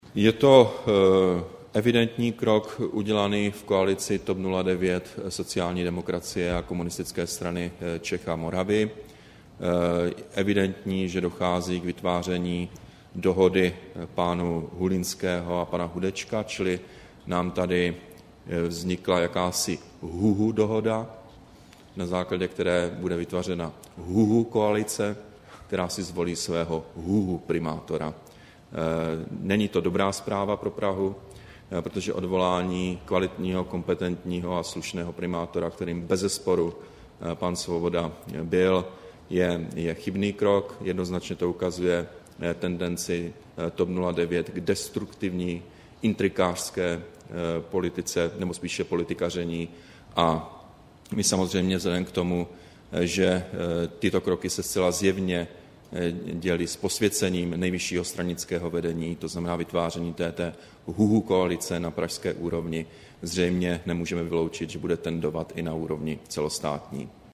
Premiér Petr Nečas dnes komentoval odvolání pražského primátora Bohuslava Svobody.